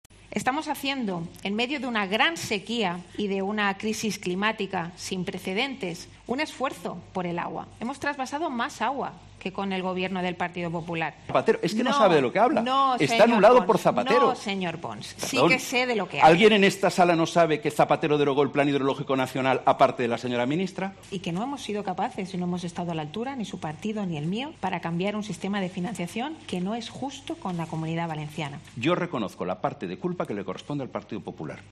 Han sido varios los momentos en que la tensión ha aumentado entre los diferentes candidatos al Congreso por Valencia que han participado en El debate Clave, organizado por Las Provincias y COPE.